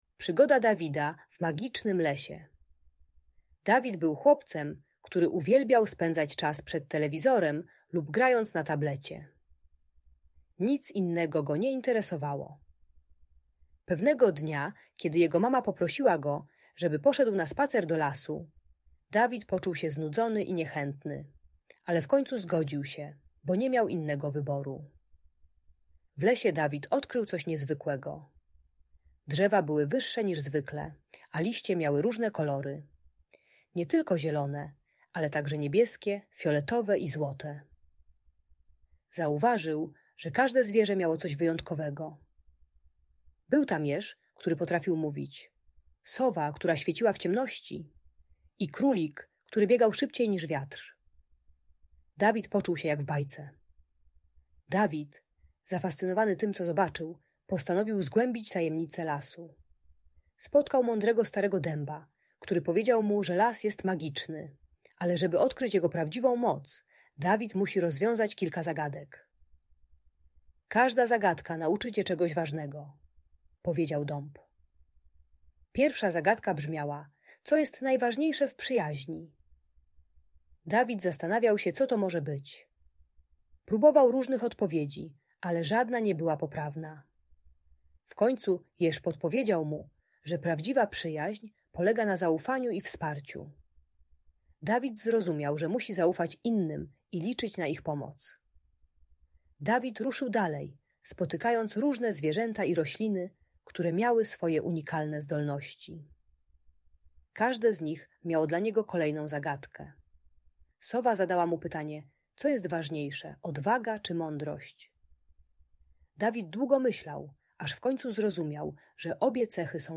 Przygoda Dawida w magicznym lesie - historia - Audiobajka